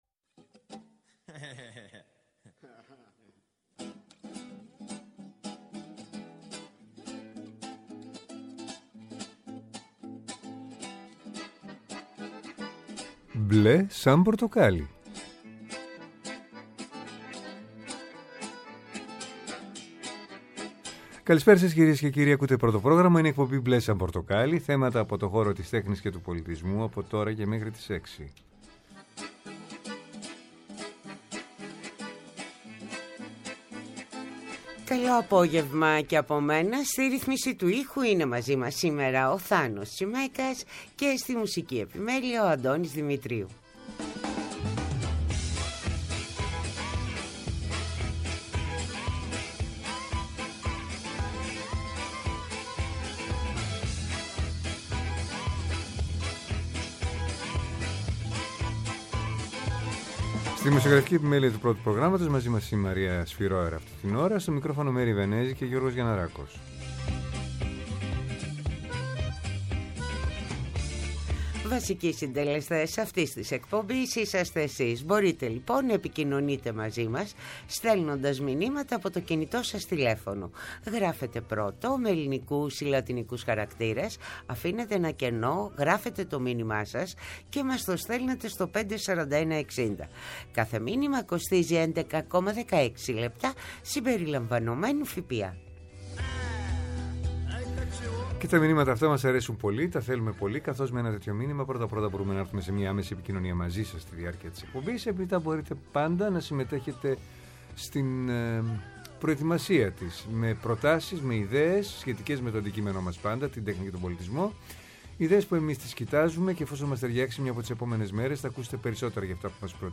Καλεσμένοι μας σήμερα τηλεφωνικά: